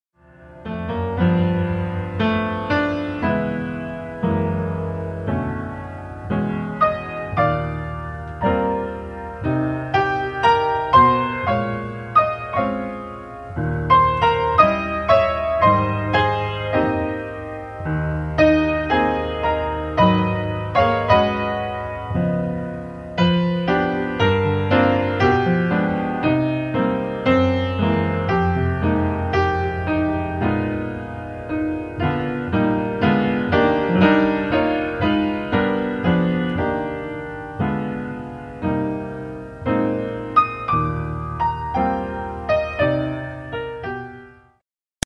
Just me and a piano.
I have to admit that the quality is not that great..